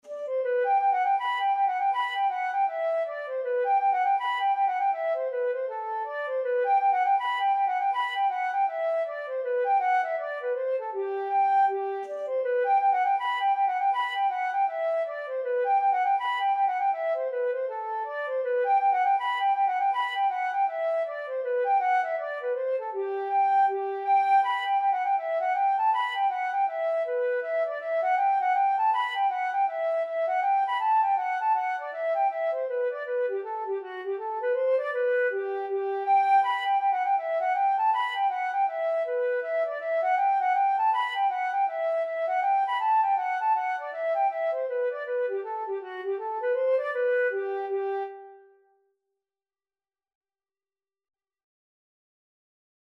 Flute version
G major (Sounding Pitch) (View more G major Music for Flute )
2/2 (View more 2/2 Music)
Flute  (View more Easy Flute Music)
Traditional (View more Traditional Flute Music)